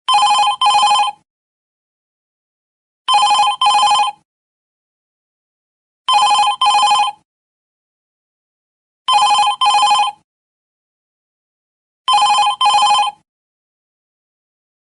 Звонок винтажного телефона - Вариант 2